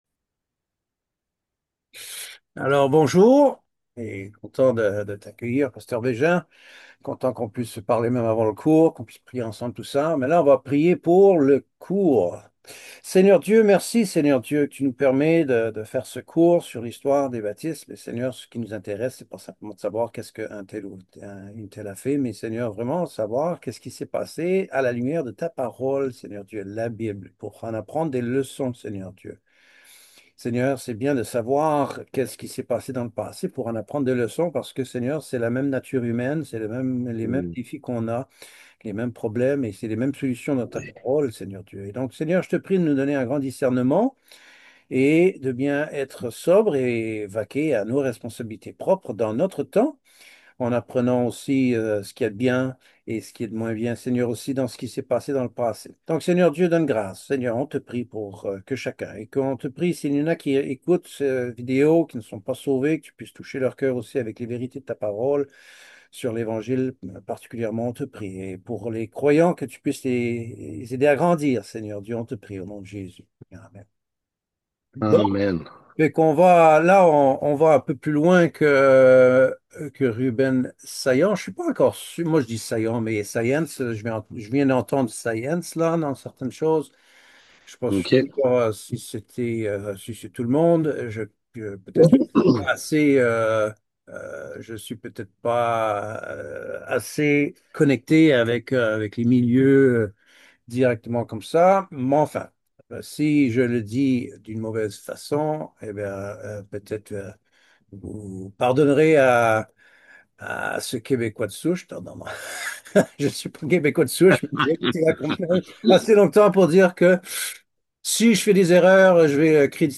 Ecoutez l’audio de la présentation sur ce sujet MP3